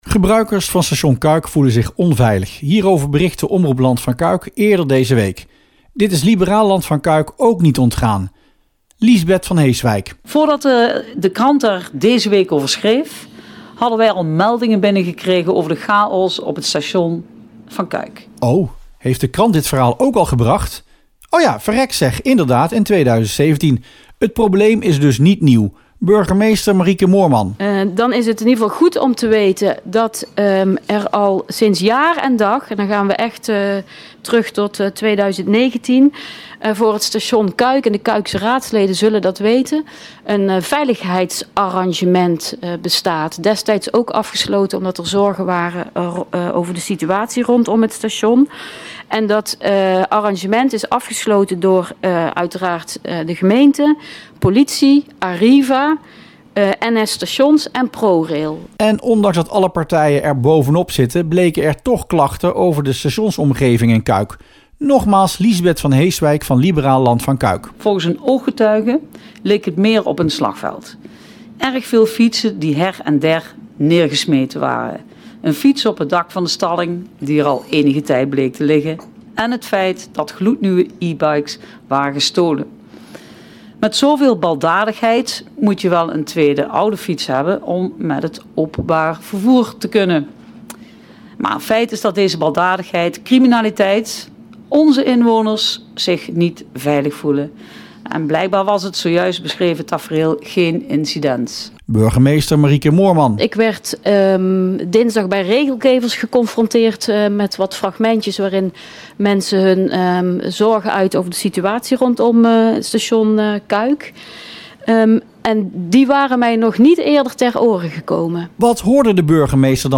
Politiekverslag